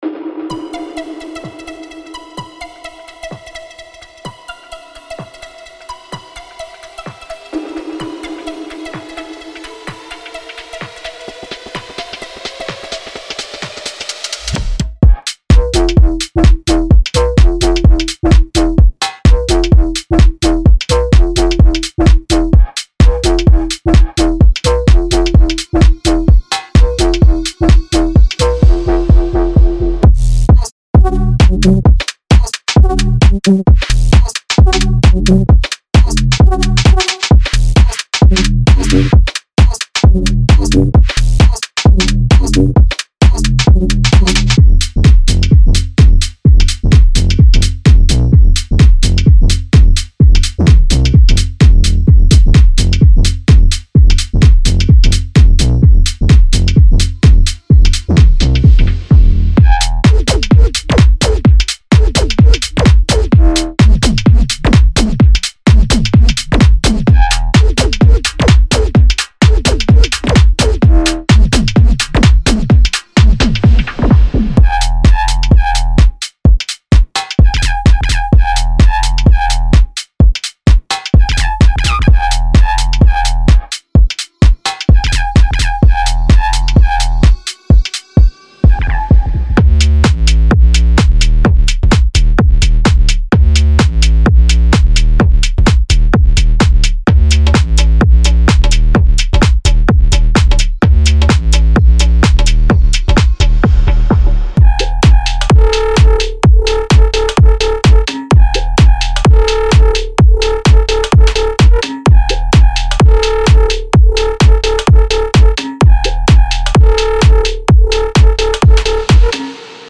Type: Serum Samples
Future Bass House